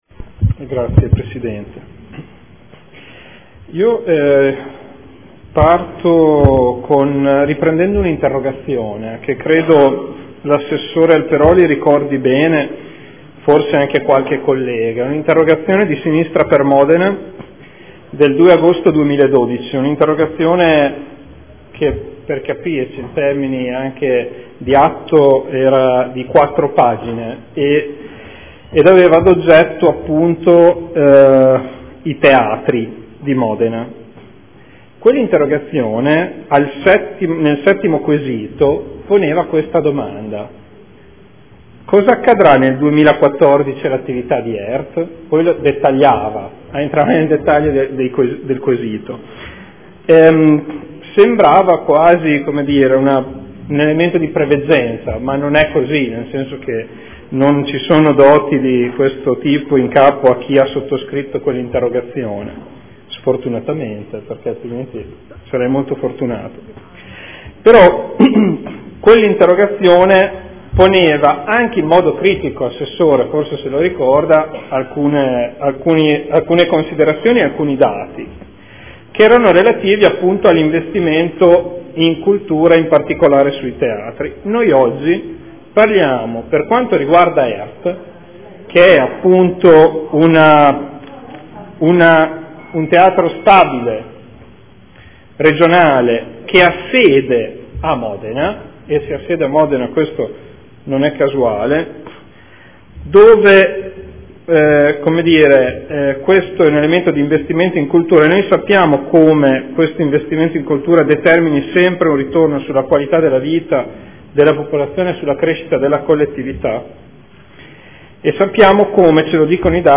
Federico Ricci — Sito Audio Consiglio Comunale
Seduta del 23/01/2014 Adesione del Comune di Bologna a Emilia Romagna Teatro Fondazione in qualità di socio fondatore necessario.